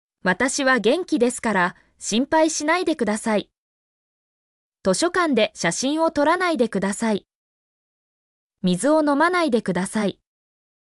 mp3-output-ttsfreedotcom-74_s6V3wdpf.mp3